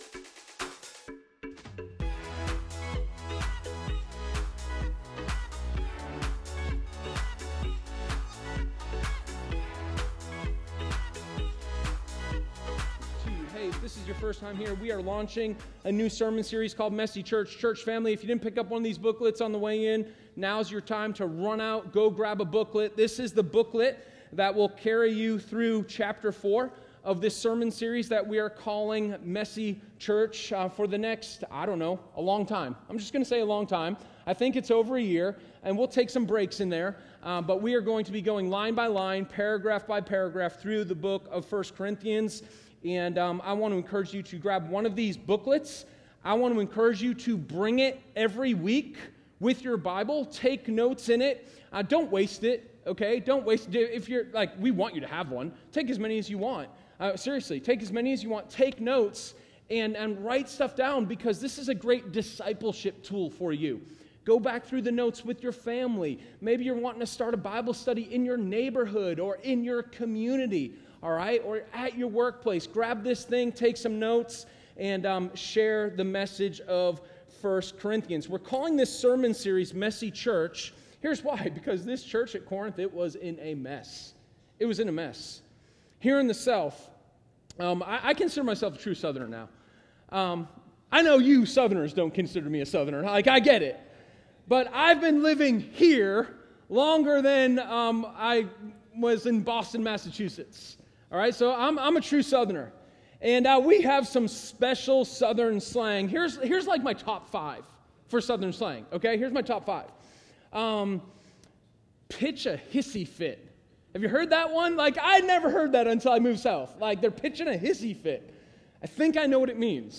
Sermon09_12_Messed-Up-People-and-the-Grace-of-God.m4a